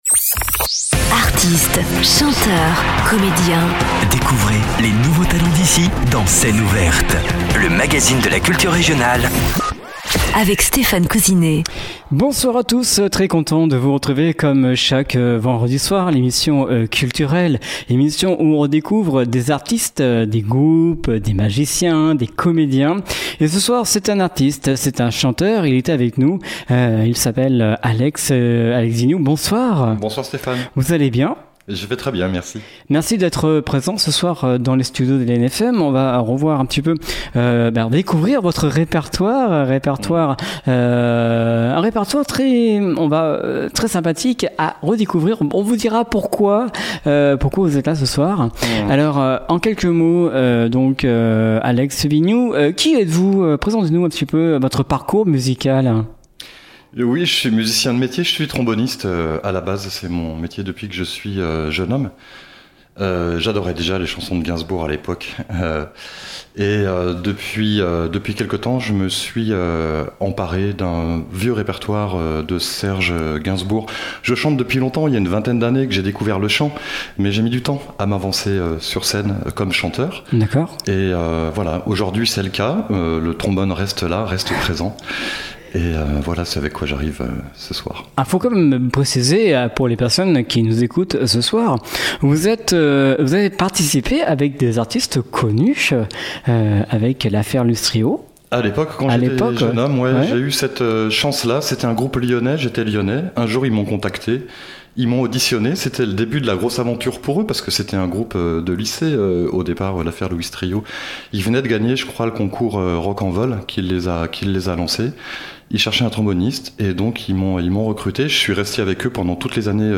Nous utilisons aussi le trombone et la trompette